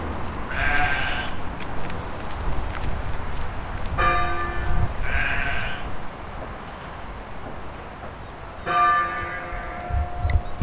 • sheep.baaing and one hit church bellwav.wav
Recorded with a Tascam DR 40.